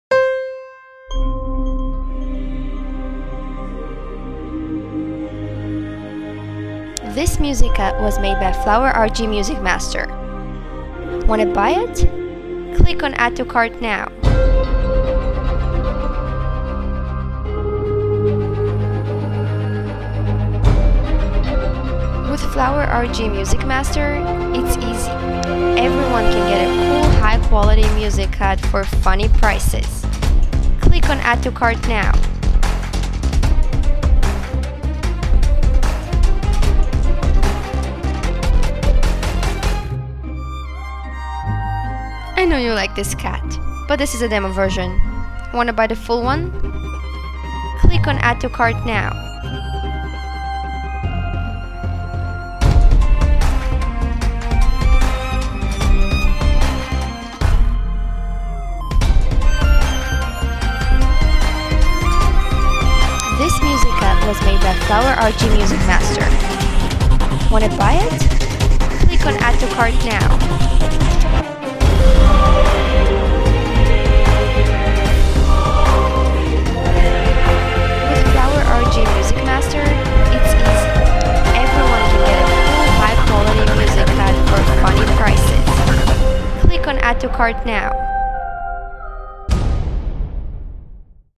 OMG this dramatic cut is EVERYTHING !